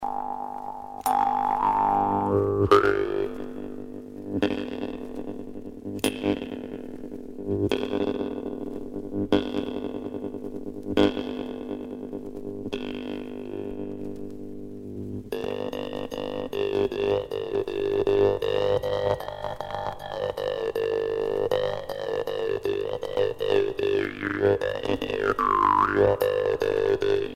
Berceuse, selon Mario Ruspoli
enfantine : berceuse
Les guimbardes